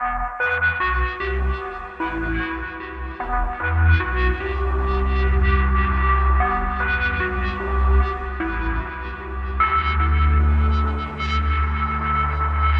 标签： 75 bpm Chill Out Loops Synth Loops 2.15 MB wav Key : E
声道立体声